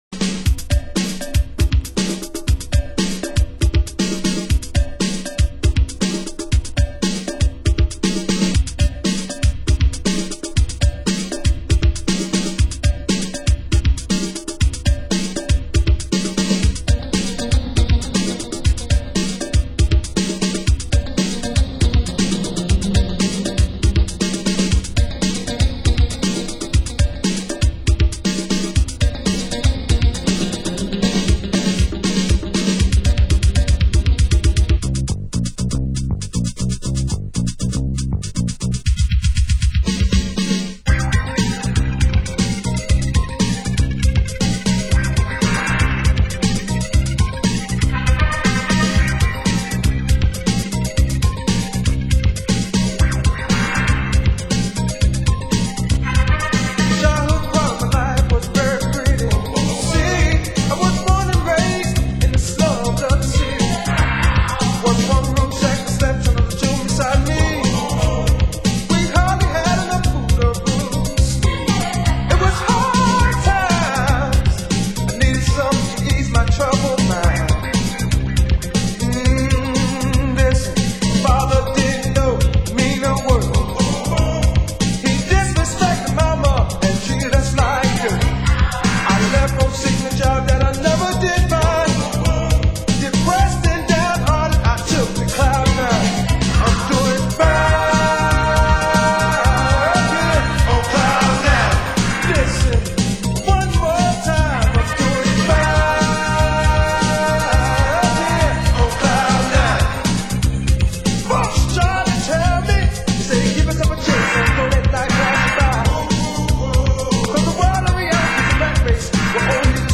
Genre: Old Skool Electro
Vocal Dance Mix